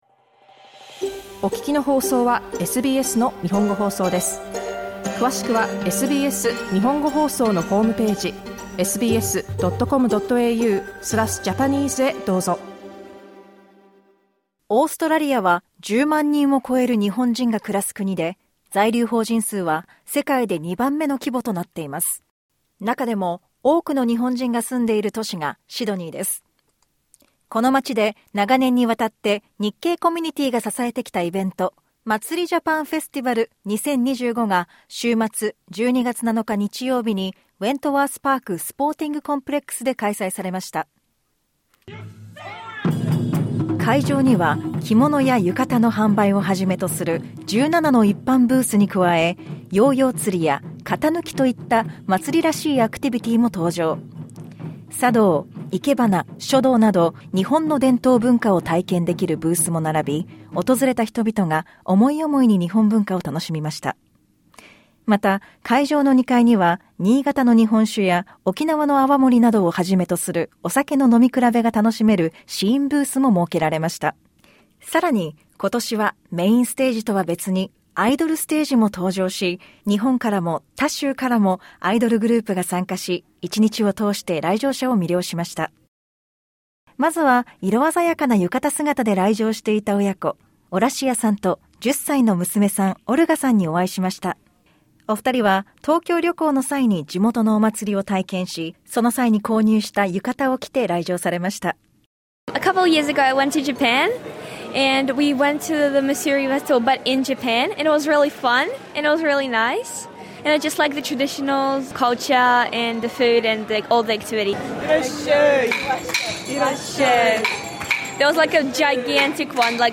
Source: SBS / At Matsuri Japan Festival 2025 in Sydney